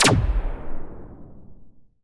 8 bits Elements
Power Laser Guns Demo
LaserGun_37.wav